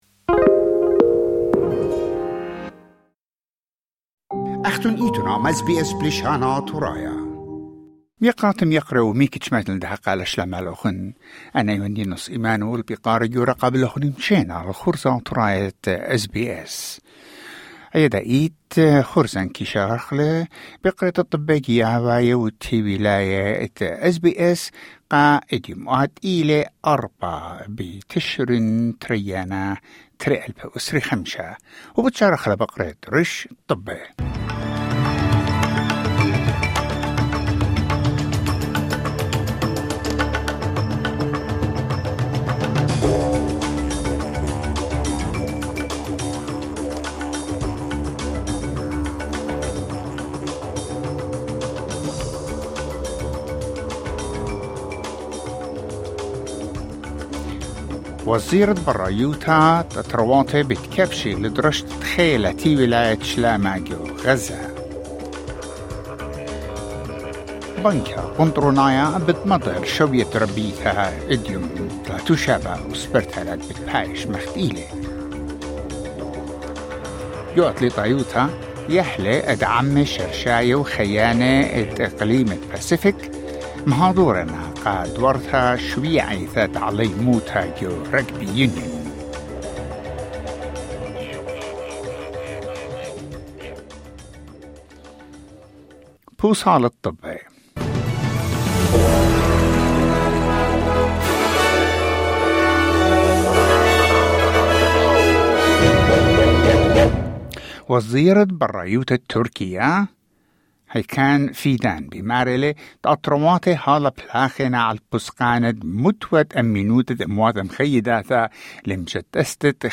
News Bulletin: 4 November 2025